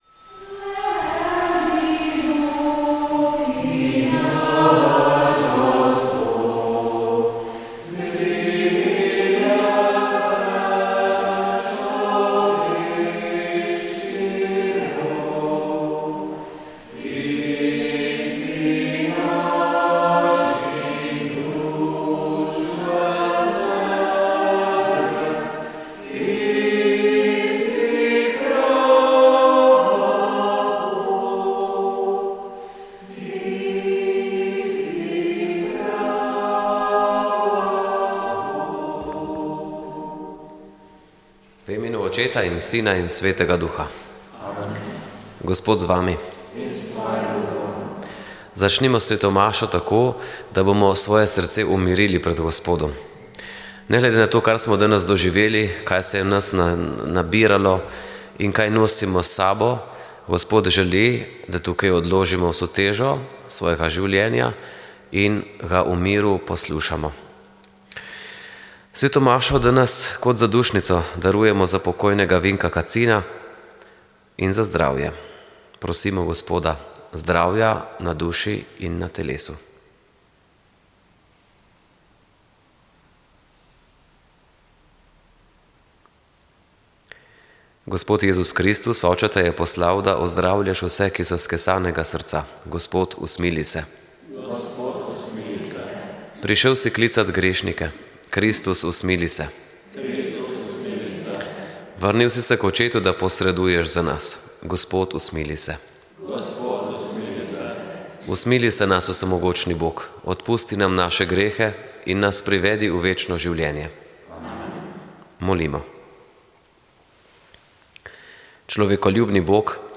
Sveta maša
Sv. maša iz stolne cerkve sv. Nikolaja v Murski Soboti 19. 7.